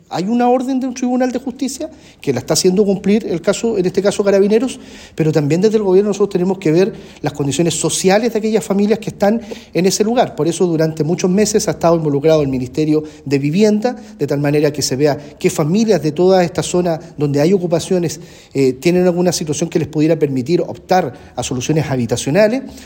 El delegado presidencial del Bío Bío, Eduardo Pacheco, confirmó que se trata de un desalojo ordenado por la Corte Suprema; sin embargo, también dijo que el Gobierno debe buscar hacerse cargo de mejorar las condiciones de quienes se mantenían en la toma.